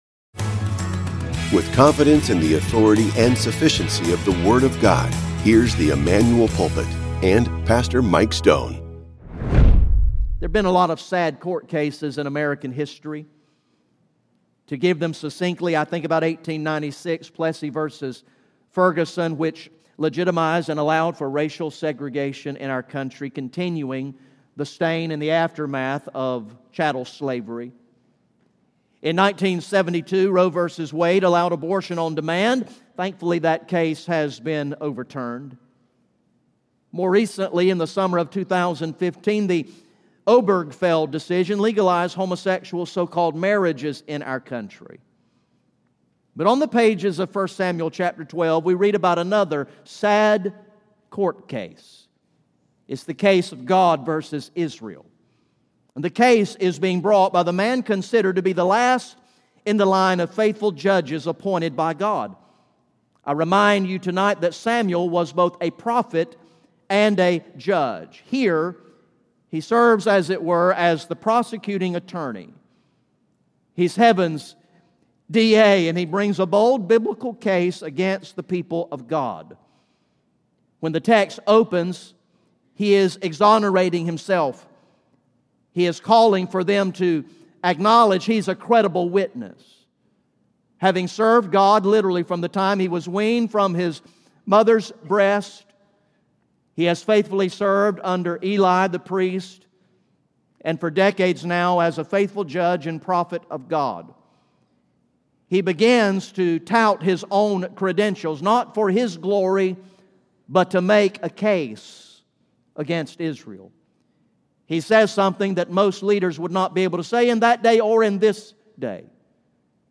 GA Message #19 from the sermon series titled “Long Live the King!